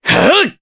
Kasel-Vox_Casting3_jp.wav